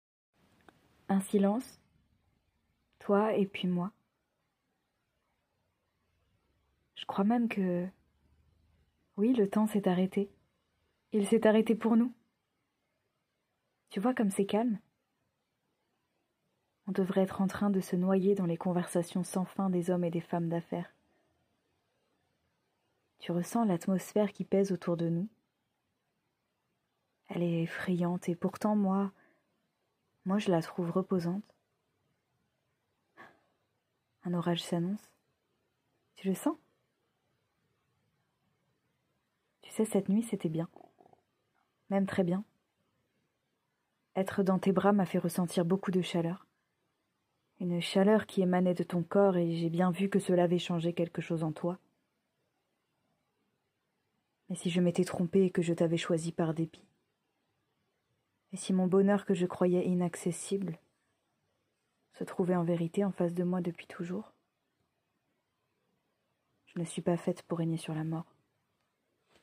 Voix off
Extrait voix
15 - 45 ans